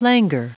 Prononciation du mot languor en anglais (fichier audio)
languor.wav